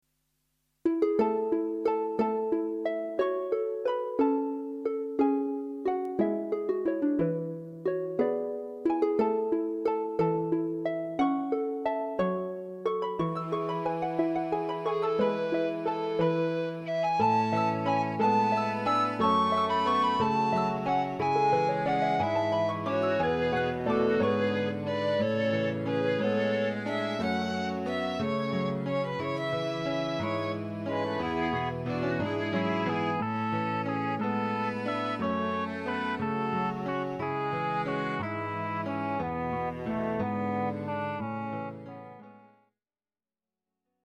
Hierbij een aantal fragmenten op basis van inheemse en folkloristische instrumenten.